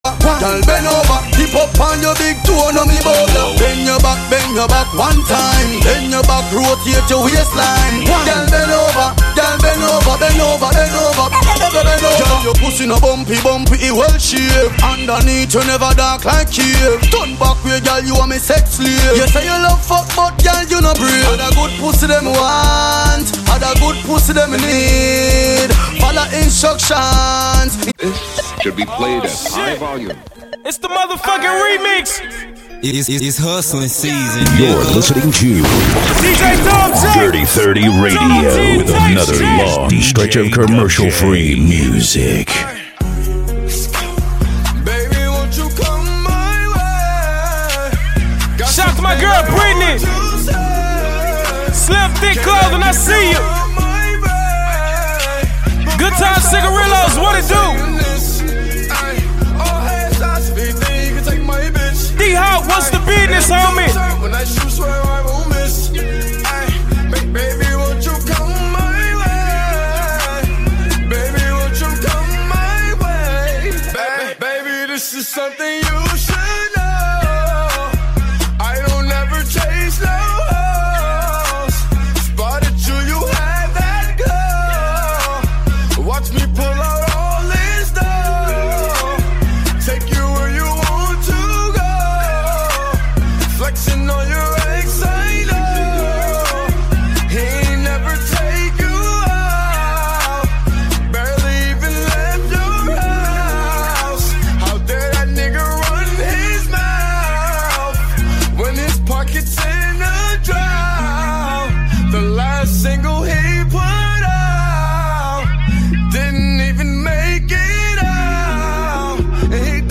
Hiphop
Description : Turnt Chill Song For The Club